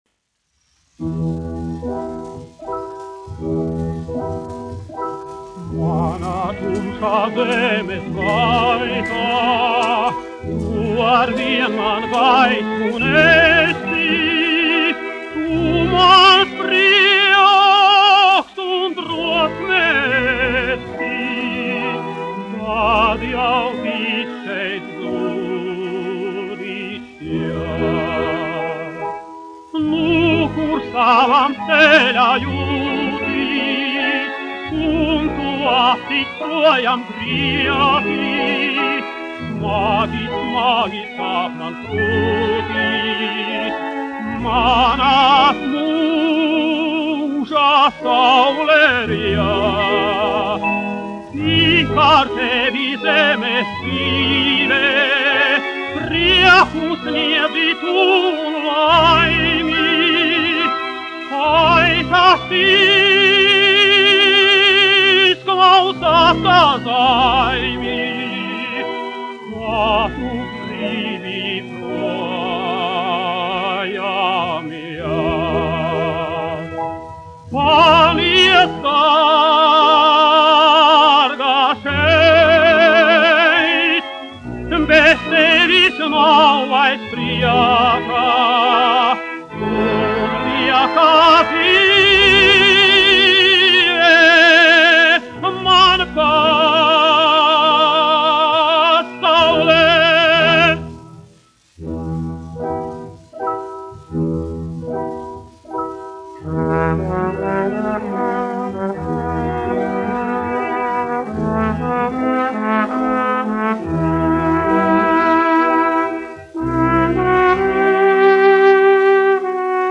1 skpl. : analogs, 78 apgr/min, mono ; 25 cm
Populārā mūzika -- Itālija
Latvijas vēsturiskie šellaka skaņuplašu ieraksti (Kolekcija)